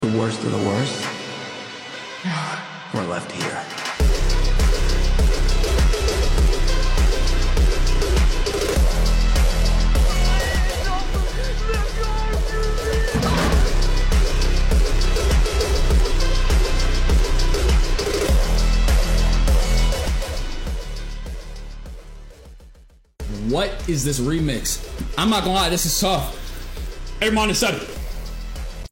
Remix)(Slowed